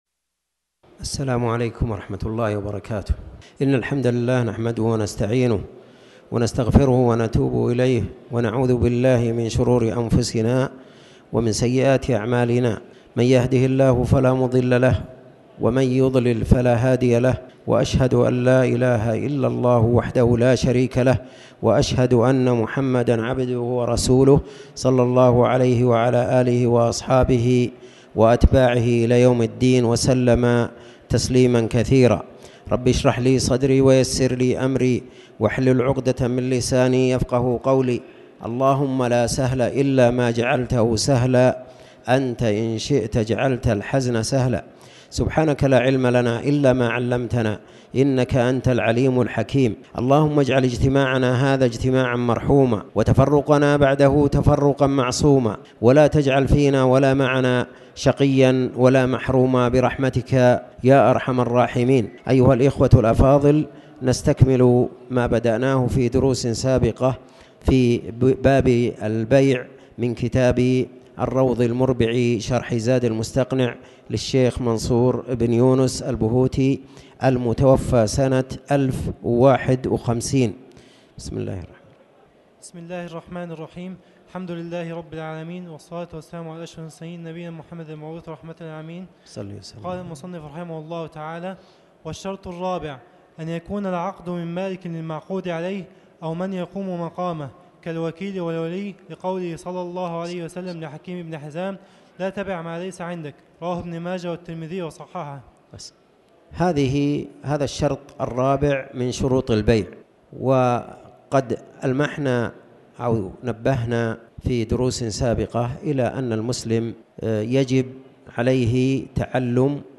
تاريخ النشر ٢٢ ربيع الثاني ١٤٣٩ هـ المكان: المسجد الحرام الشيخ